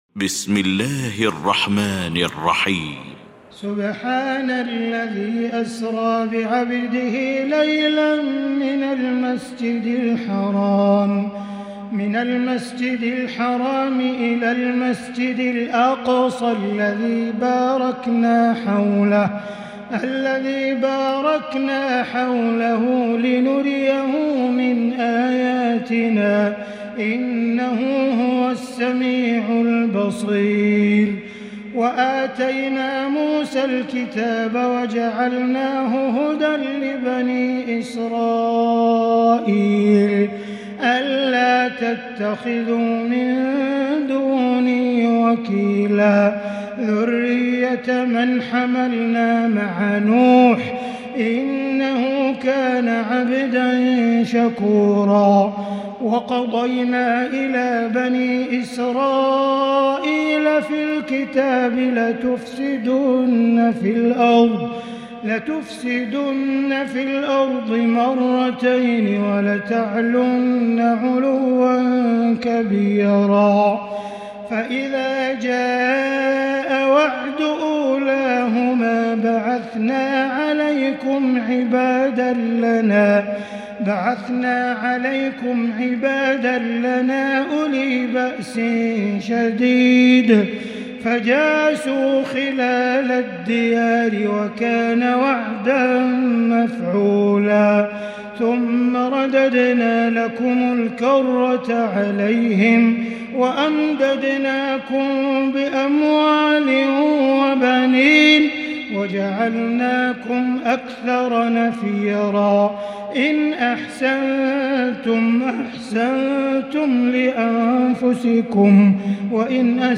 المكان: المسجد الحرام الشيخ: سعود الشريم سعود الشريم معالي الشيخ أ.د. عبدالرحمن بن عبدالعزيز السديس الإسراء